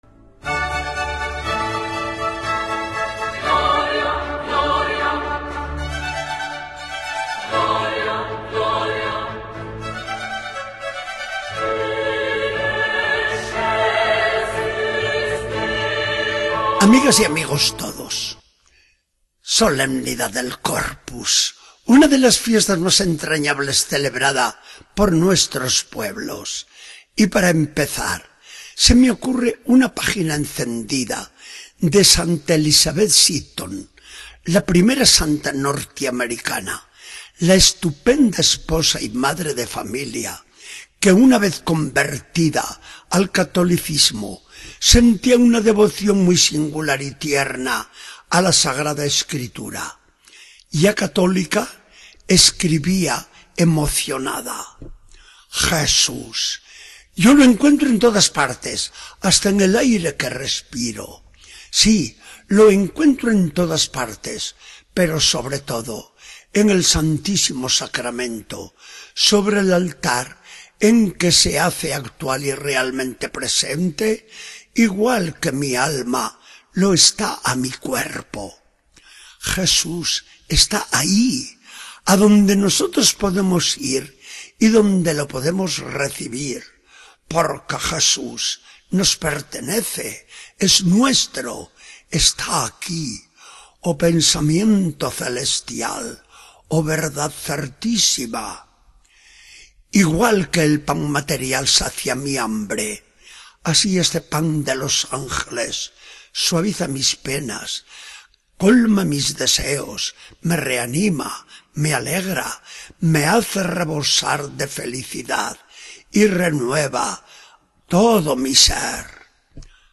Charla del día 22 de junio de 2014. Del Evangelio según San Juan 6, 51-58.